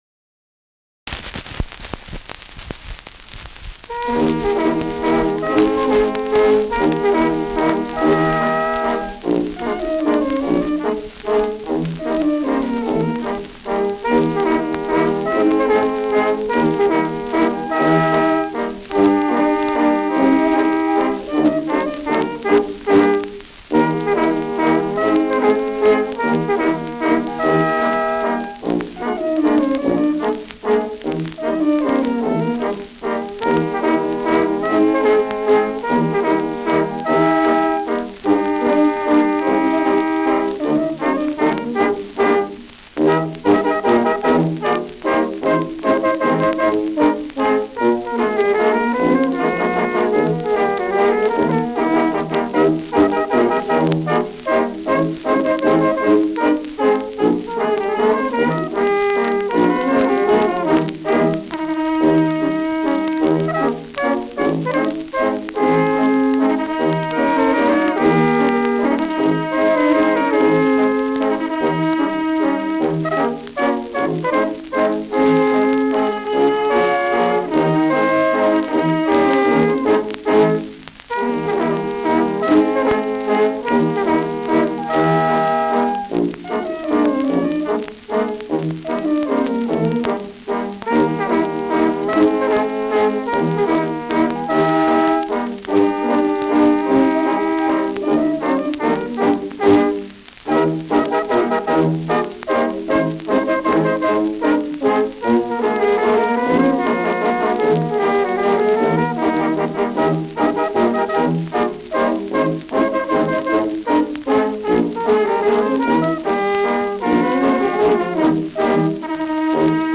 Schellackplatte
Stärkeres Grundrauschen : Durchgehend leichtes bis stärkeres Knacken
Dachauer Bauernkapelle (Interpretation)